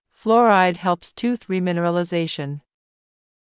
ﾌﾛｰﾗｲﾄﾞ ﾍﾙﾌﾟｽ ﾄｩｰｽ ﾘﾐﾈﾗﾘｾﾞｰｼｮﾝ